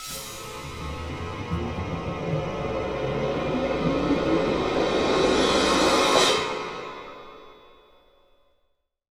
Index of /90_sSampleCDs/Best Service ProSamples vol.33 - Orchestral Loops [AKAI] 1CD/Partition C/CRESCENDOS